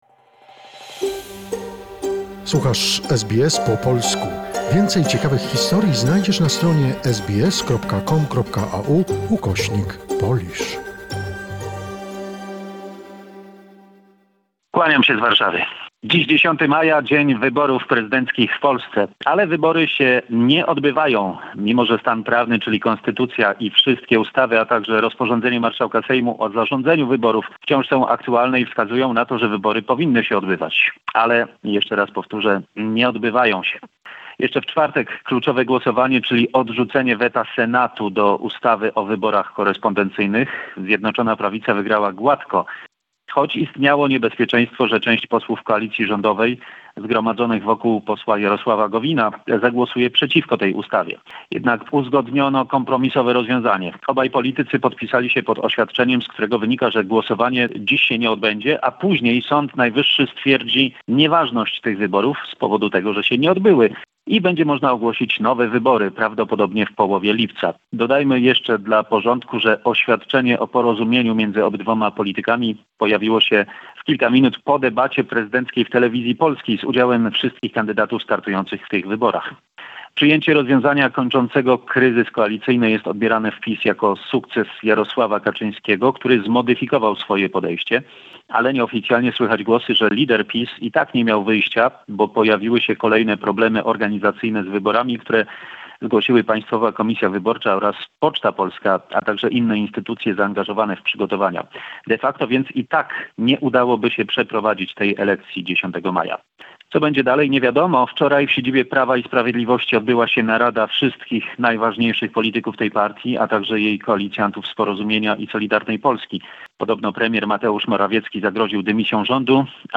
weekly report from Poland